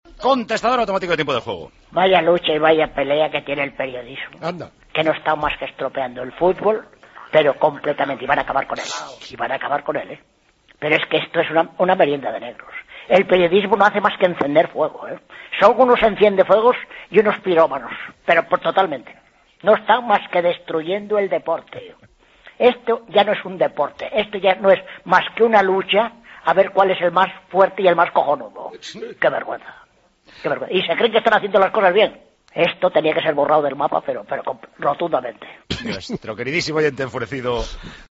El oyente enfurecido